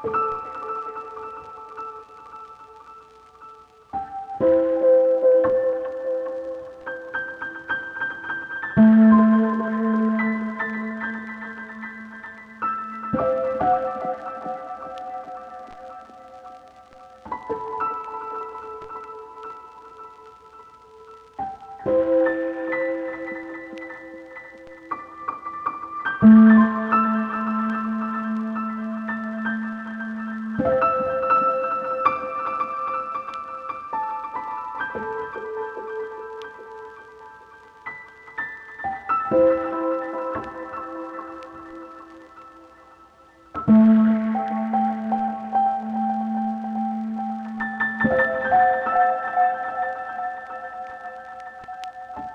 This Piano sounds beautiful. Here’s A little meandering overly processed loop of course 🙏🏼💗